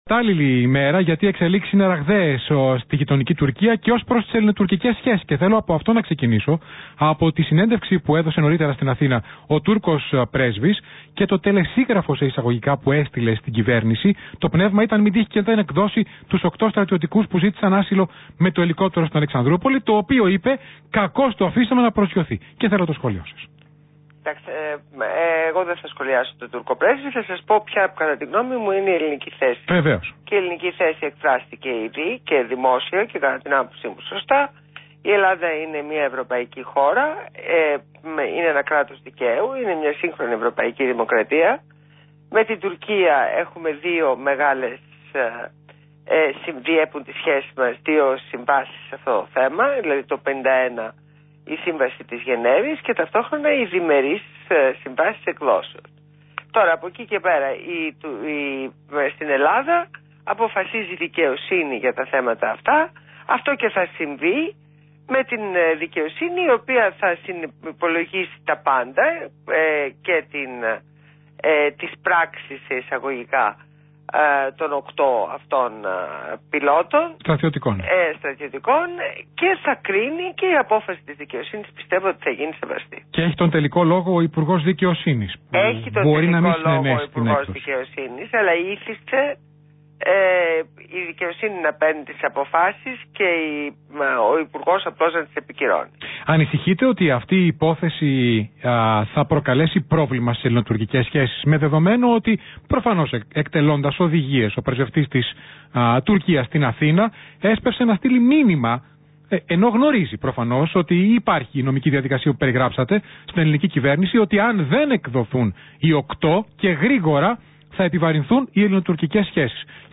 Συνέντευξη στο ραδιόφωνο REAL fm